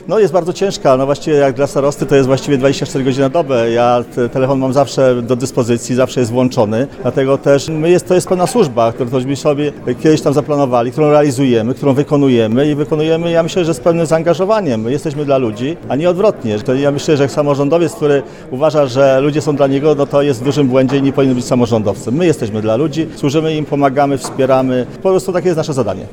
– mówi starosta tarnowski Roman Łucarz.